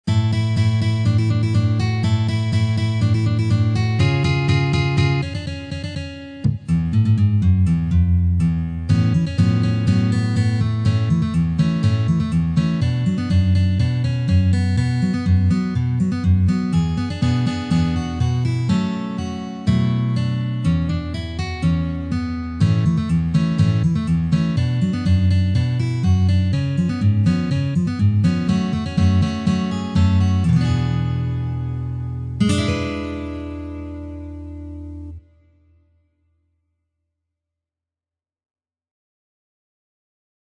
per sola chitarra
canzoni napoletane
Canzone napoletana per chitarra